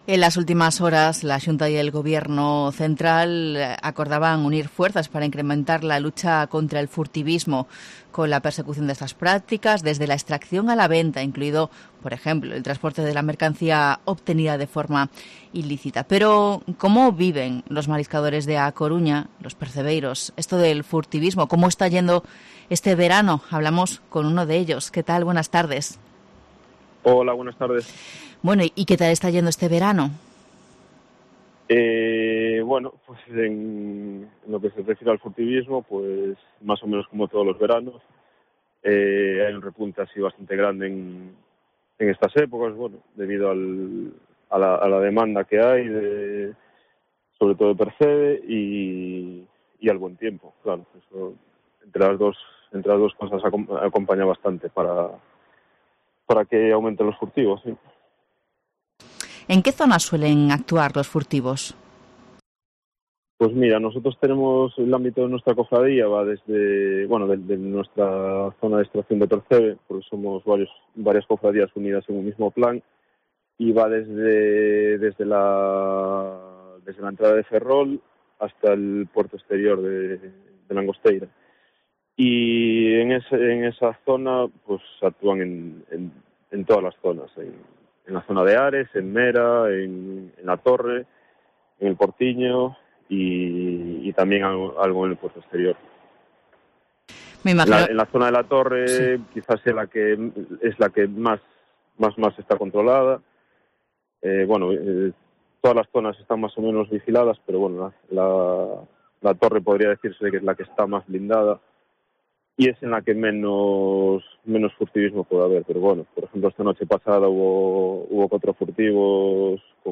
El día a día contra el furtivismo en A Coruña: habla un percebeiro
Este es relato a COPE Coruña de un percebeiro que trabaja en la costa coruñesa.